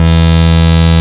74.03 BASS.wav